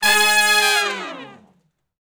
014 Long Falloff (Ab) unison.wav